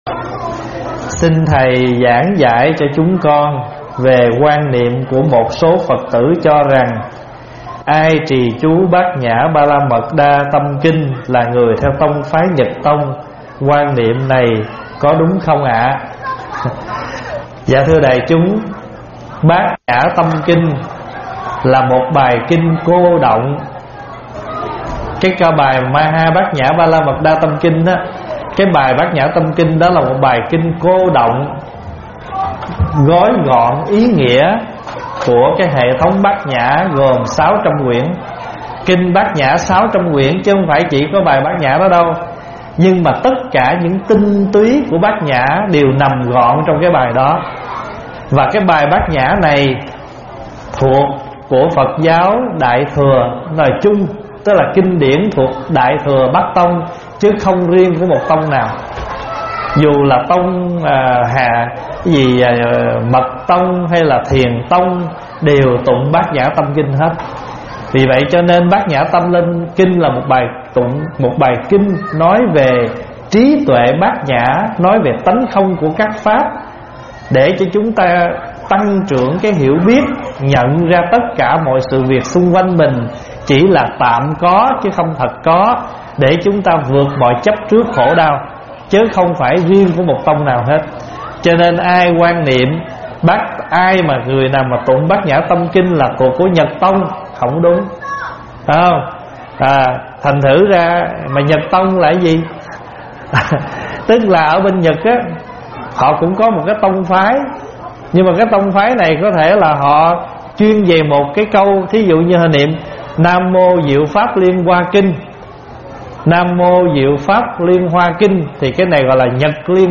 Mp3 vấn đáp Tự Kết Liễu Đời Mình Có Bị Đọa Không?